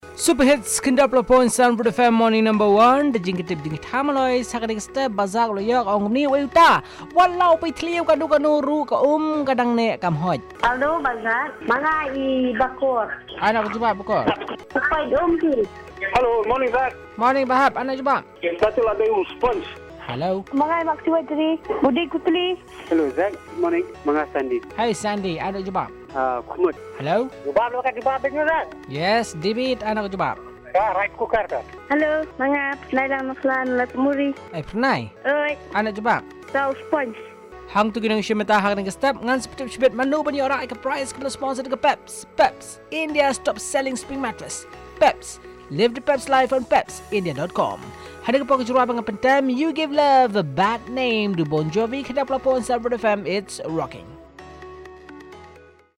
Callers with their answers